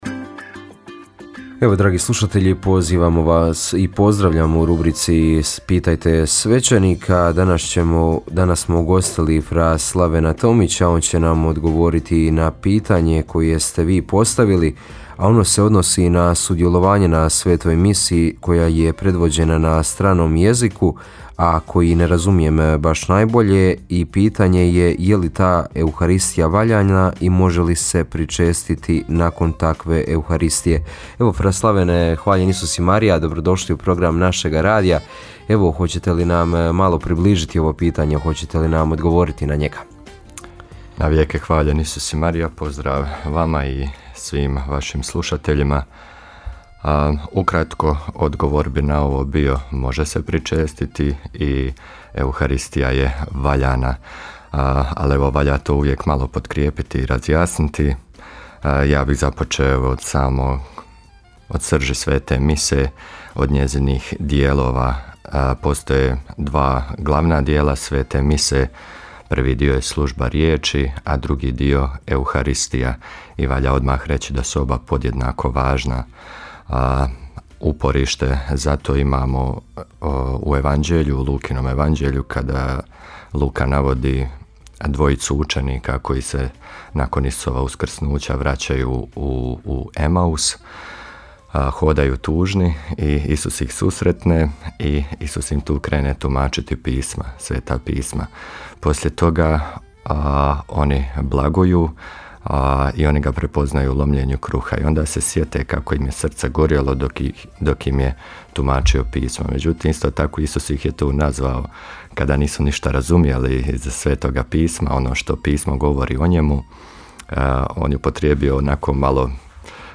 Rubrika ‘Pitajte svećenika’ u programu Radiopostaje Mir Međugorje je ponedjeljkom od 8 sati i 20 minuta te u reprizi ponedjeljkom navečer u 20 sati. U njoj na pitanja slušatelja odgovaraju svećenici, suradnici Radiopostaje Mir Međugorje.